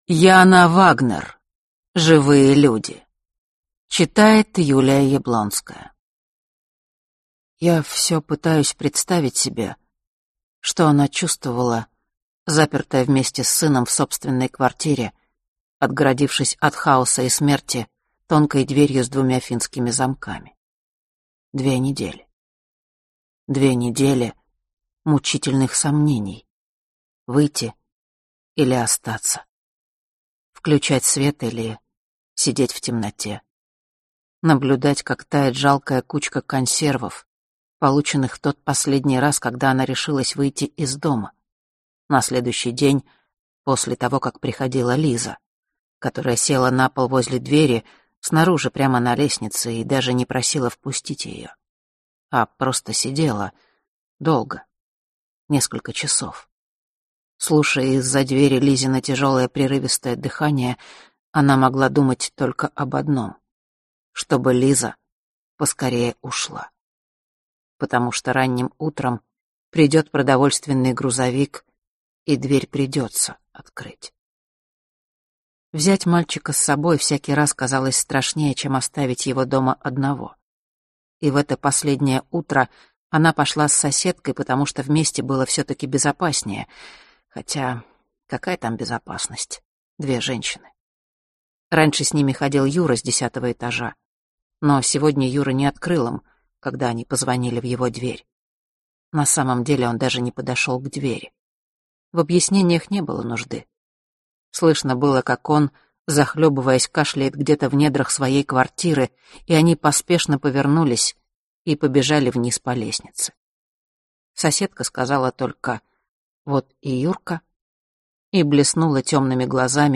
Аудиокнига Живые люди | Библиотека аудиокниг